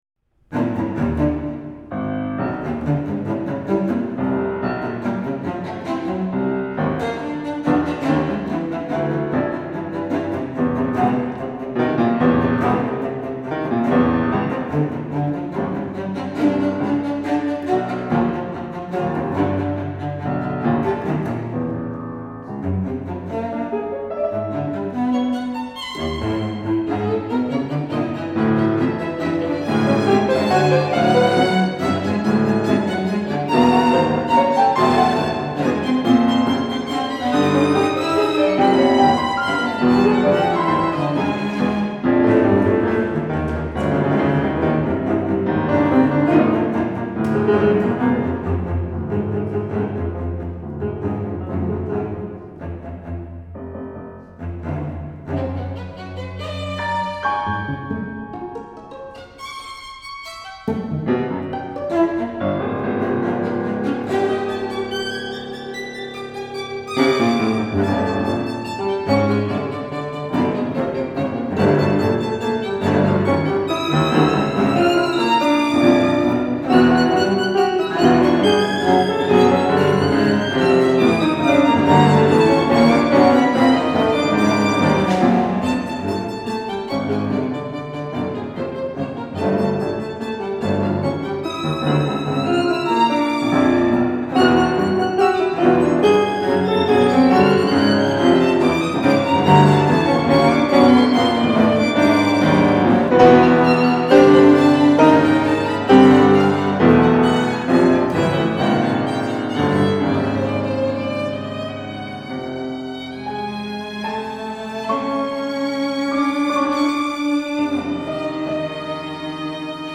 violin, Cello, Piano